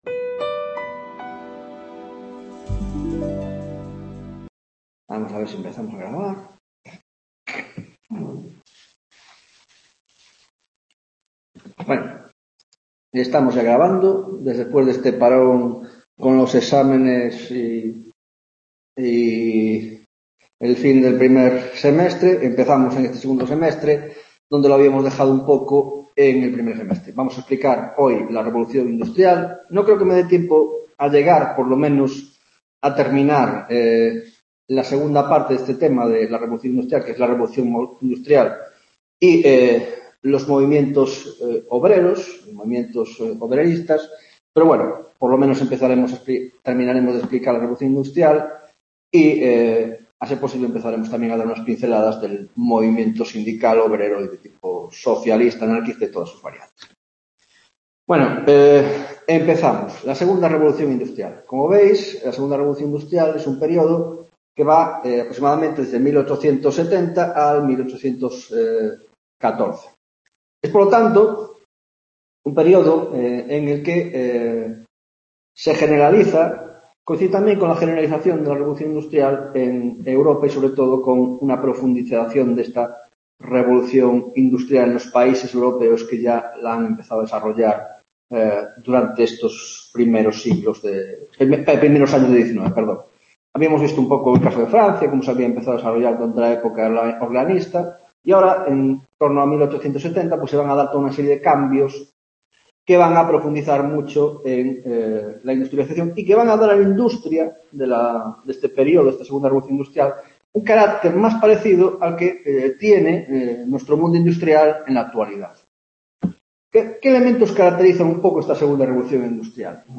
13ª Tutoría de Historia Contemporánea - 2ª Revolución Industrial 1